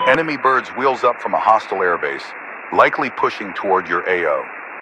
Radio-commandNewEnemyAircraft4.ogg